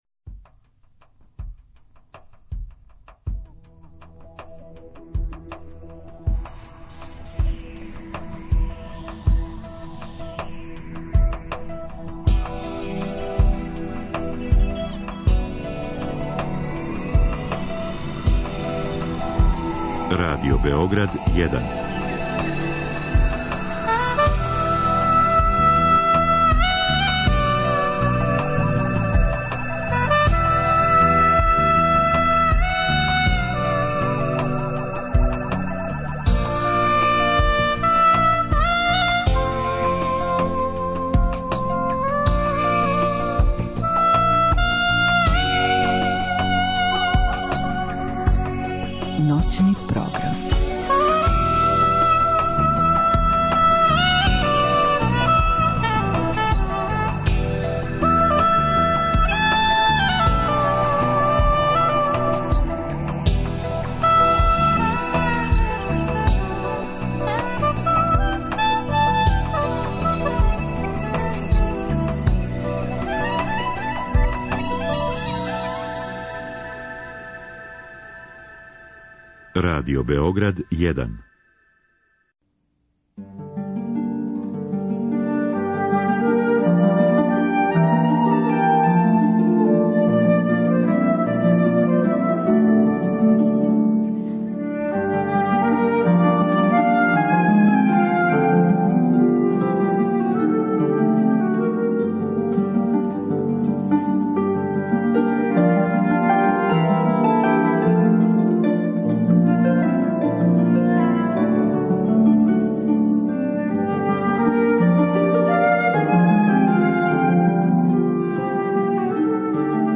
Ove noći uživaćemo u zvuku orgulja.